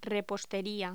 Locución: Repostería
Sonidos: Voz humana